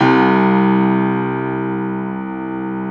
53d-pno02-C0.aif